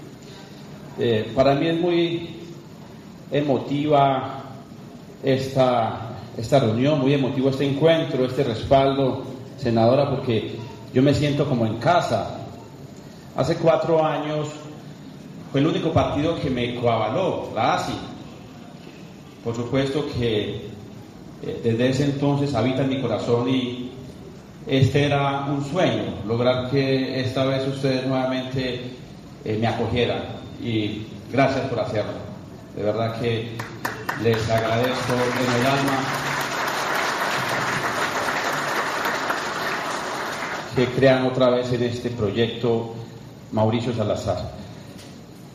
En rueda de prensa donde se hizo la presentación de los candidatos a los diferentes cargos de elección del departamento de Risaralda